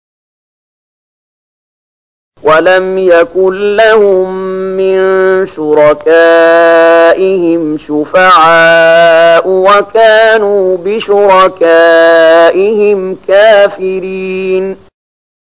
030013 Surat Ar-Ruum ayat 13 dengan bacaan murattal ayat oleh Syaikh Mahmud Khalilil Hushariy: